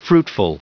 Prononciation du mot fruitful en anglais (fichier audio)
Prononciation du mot : fruitful